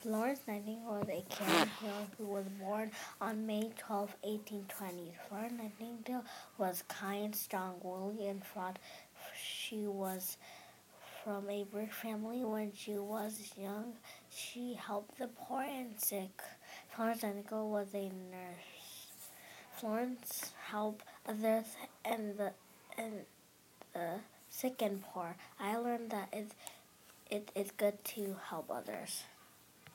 Nightingale